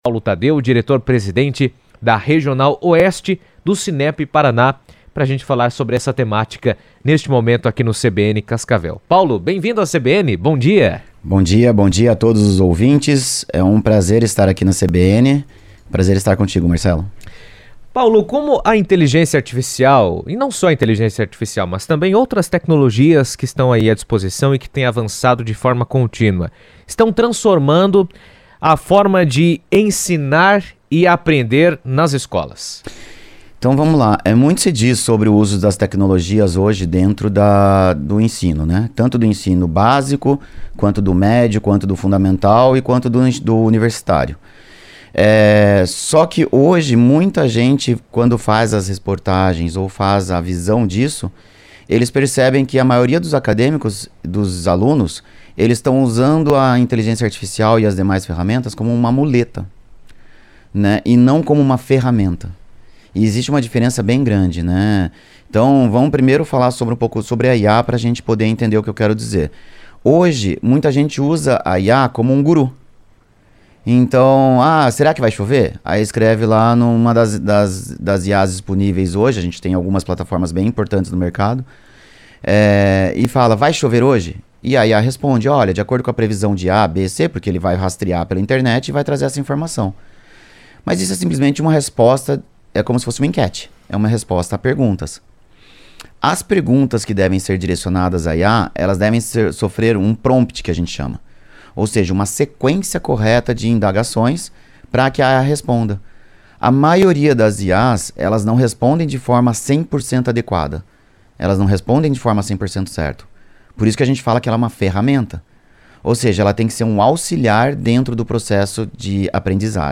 O impacto da inteligência artificial no ensino tem aberto espaço para inovação, trazendo novas possibilidades de aprendizado personalizado e maior integração tecnológica nas salas de aula, mas também levanta desafios quanto à formação de professores e ao uso responsável das ferramentas. Em entrevista à CBN